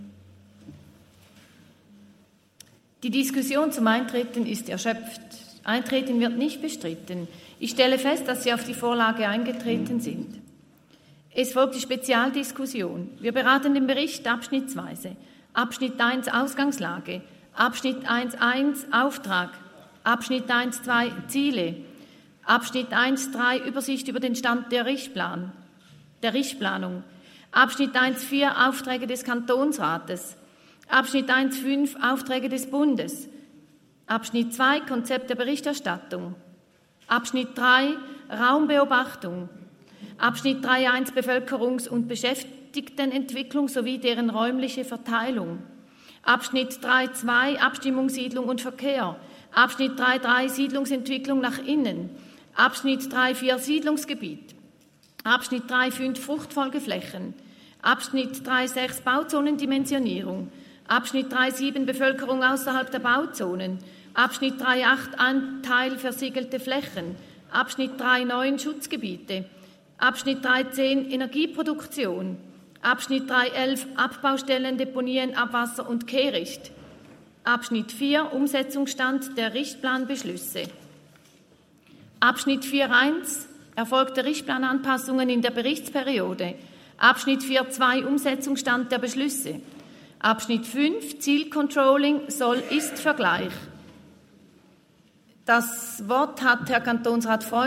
Schöb-Thal, Ratspräsidentin, stellt Eintreten auf den Bericht fest.
Session des Kantonsrates vom 18. bis 20. September 2023, Herbstsession